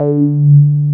RUBBER D4 P.wav